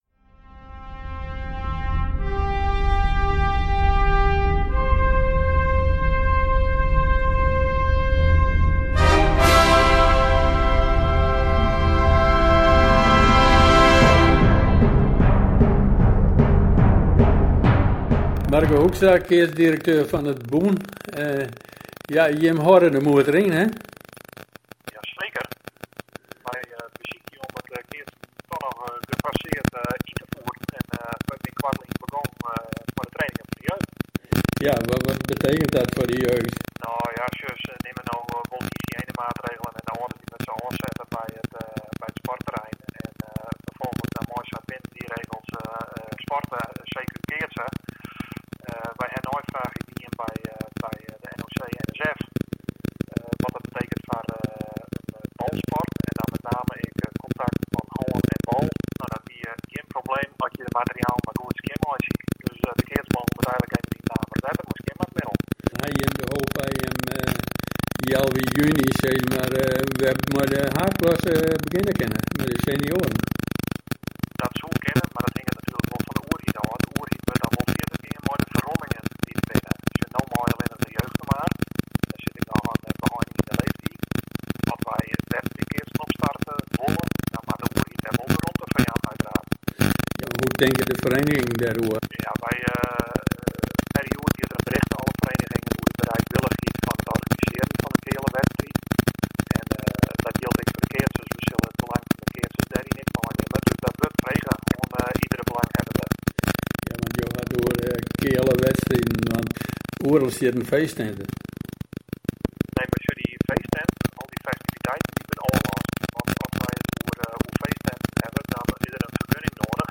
Excuus voor de slechte geluidskwaliteit.